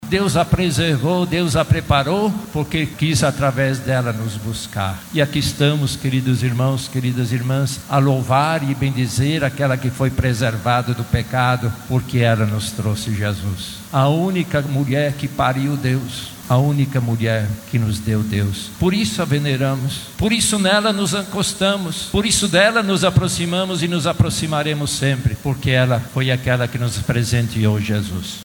Em sua homília, o Cardeal Steiner destacou a singeleza e pureza de Maria, uma mulher cheia de Deus e de simplicidade que deve inspirar a vida do cristão católico.
SONORA-3-DOM-LEONARDO-.mp3